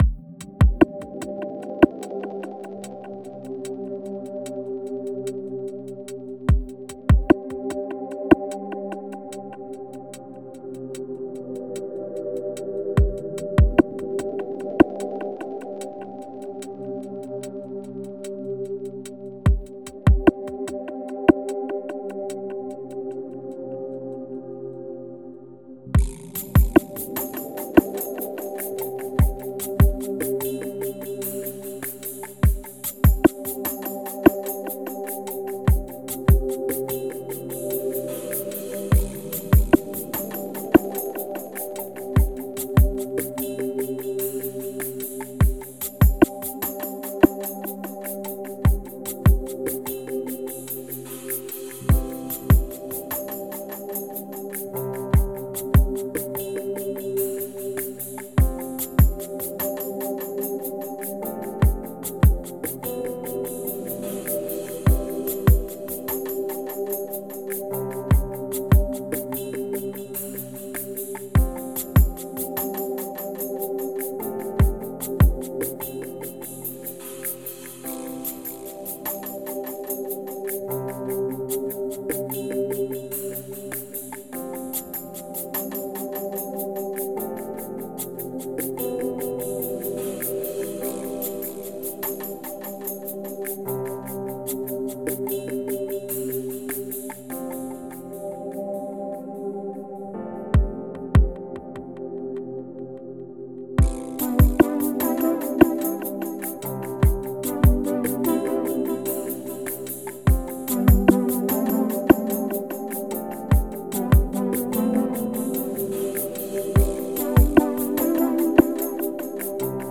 So, I guess I was in a kind of "chill the fuck out" mood?